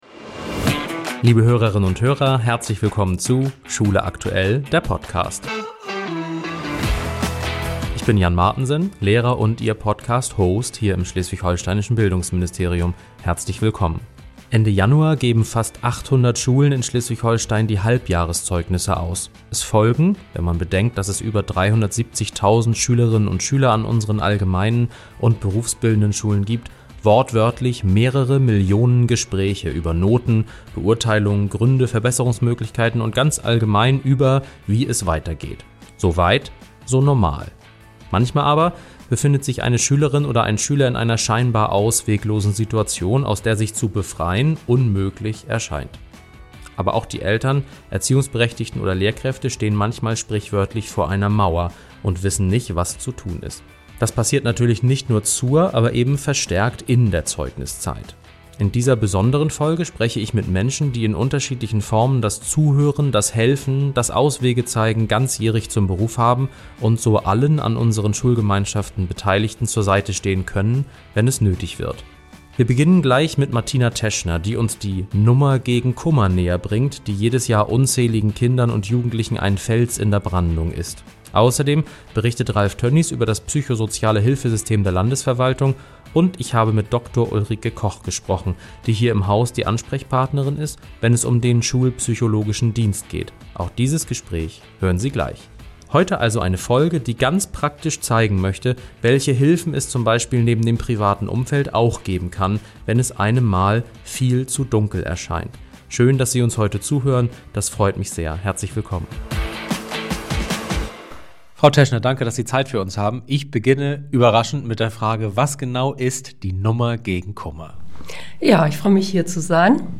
Wir sprechen mit drei Menschen, die genau diese Hilfe bieten können, zum Beispiel mit de...